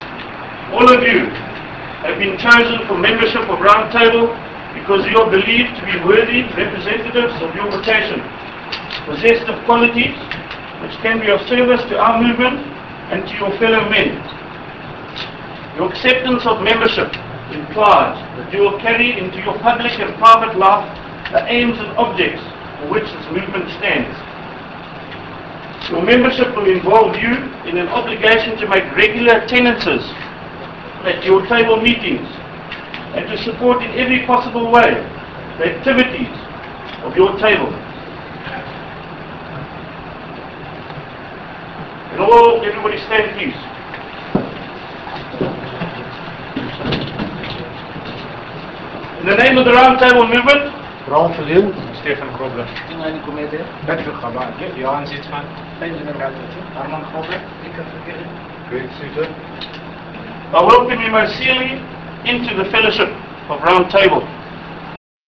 Maun 65 Charter